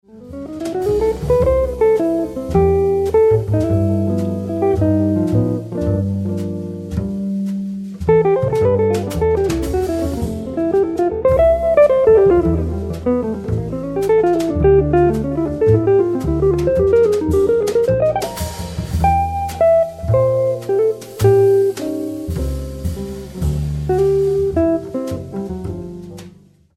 I love the fluidity of the line.